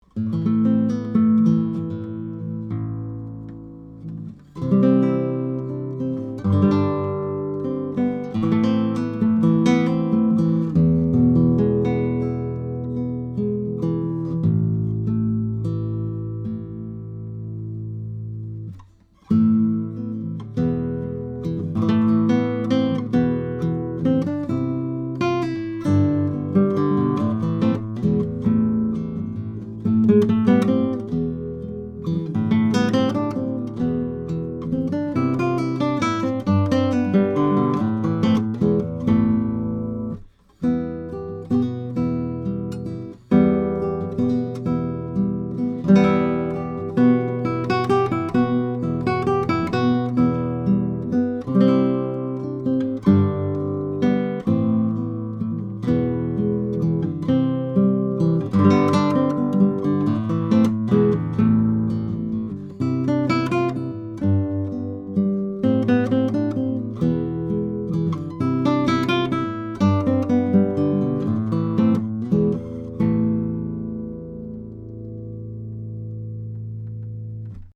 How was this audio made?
Here are a few sound files of a pair of Mini K47 on nylon 7-string guitar, going into a Trident 88 console: